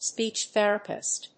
アクセントspéech thèrapist